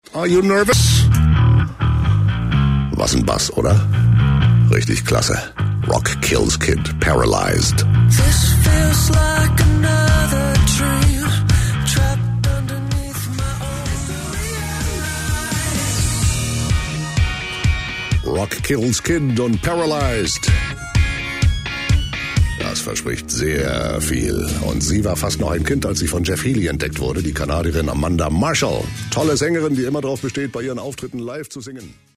An jedem Samstagmorgen ist es -nach Moeglichkeit- "Pflicht", die Sendung "hr3 Extra" zu hoeren (9-12 Uhr). Sie wird von Werner Reinke moderiert, jenem legendaeren Radiomoderator, der eine markante Stimme hat und mich spaetestens seit den 80er Jahren akustisch begleitet hat.
Ausgerechnet und erfreulicherweise in Werner Reinke`s Sendung. Genau ihm haette ich auch am ehesten zugetraut, dass er es findet und spielt…